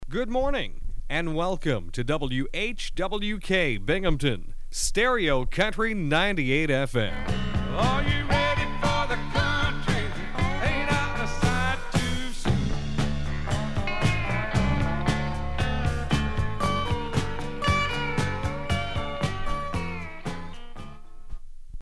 WHWK SIGN-ON.mp3